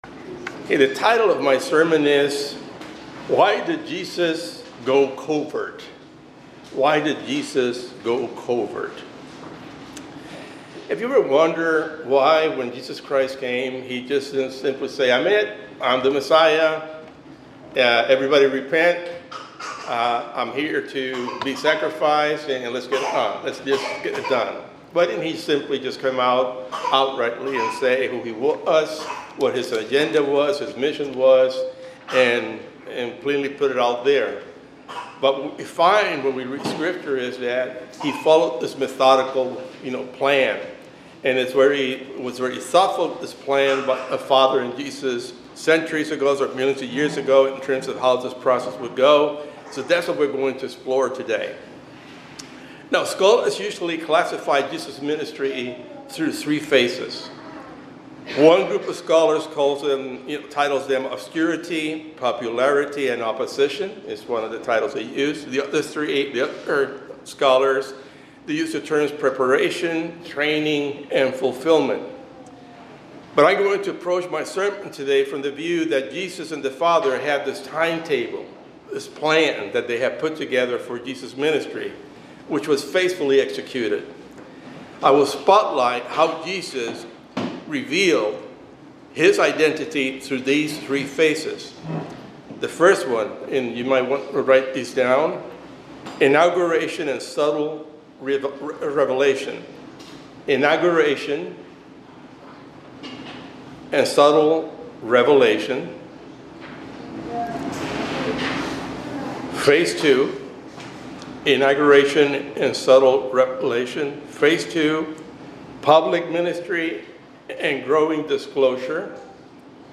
This sermon explores the question "Why did Jesus go covert?" by analyzing the strategic, prophetic, and salvific reasons behind Jesus Christ's gradual and subtle revelation of his identity and mission during his ministry. It details how Jesus followed a divinely orchestrated timetable in three distinct phases, revealing his Messiahship progressively to fulfill prophecy, avoid premature political upheaval, and prepare his followers spiritually.